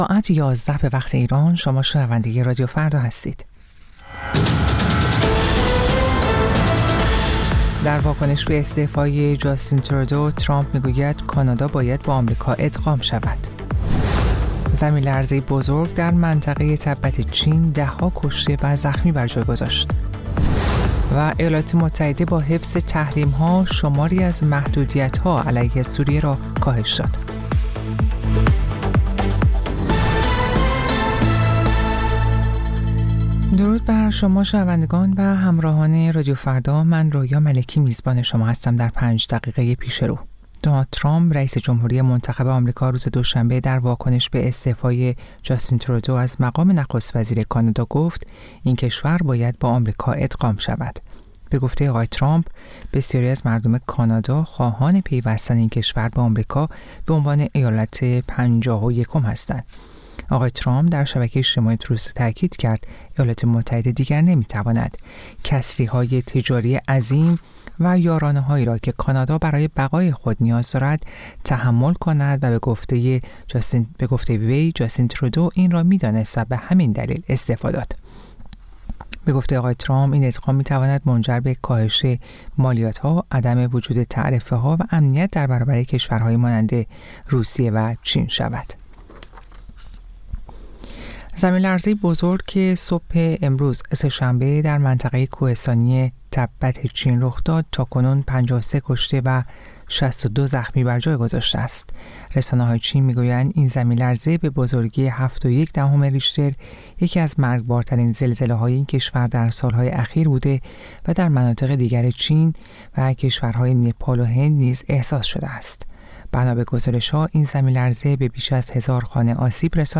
سرخط خبرها ۱۱:۰۰